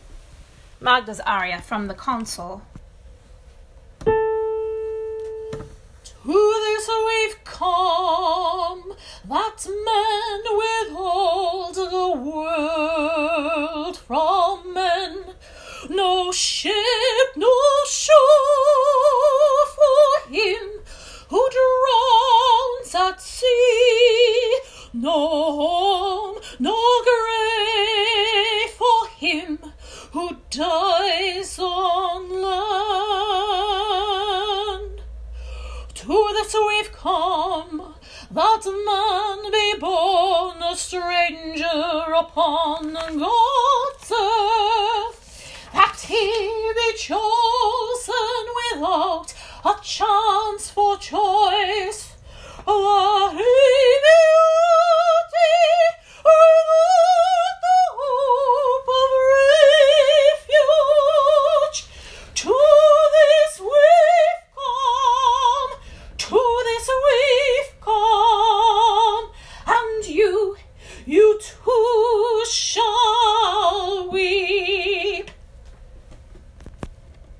Voice Samples
Current voice part: Dramatic/Wagnerian soprano.